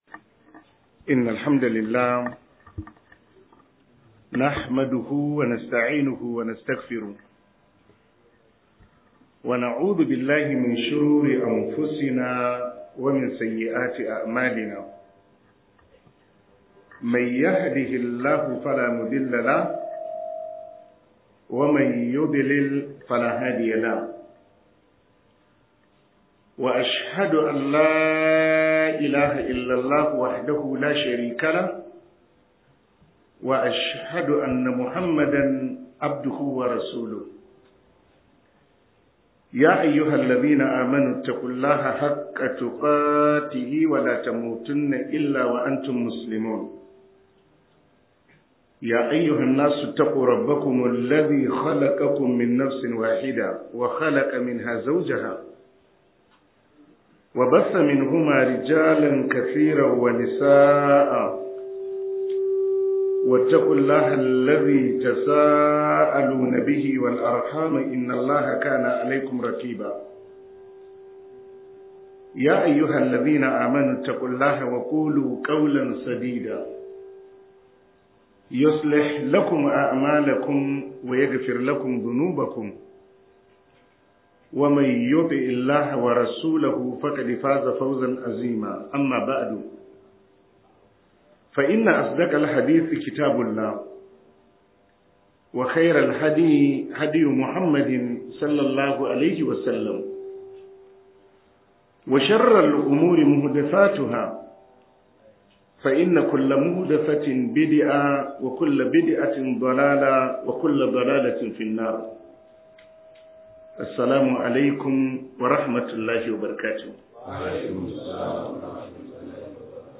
Book MUHADARA